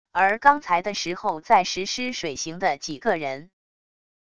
而刚才的时候在实施水刑的几个人wav音频生成系统WAV Audio Player